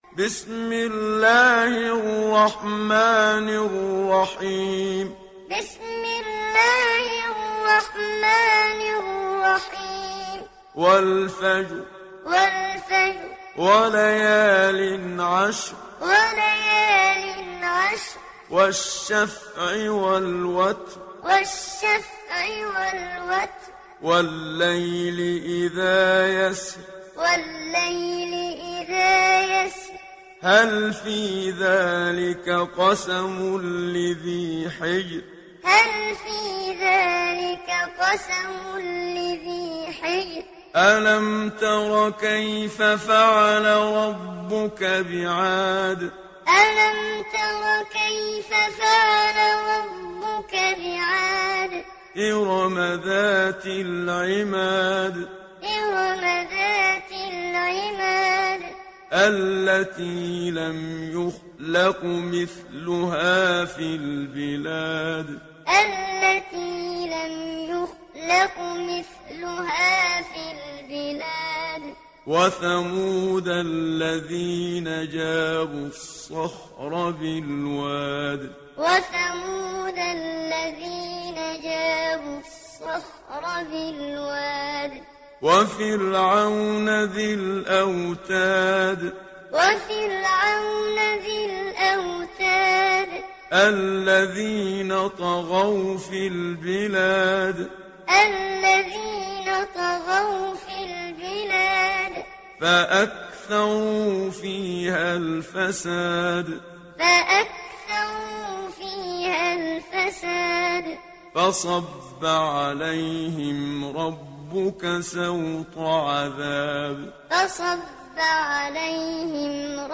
সূরা আল-ফাজর mp3 ডাউনলোড Muhammad Siddiq Minshawi Muallim (উপন্যাস Hafs)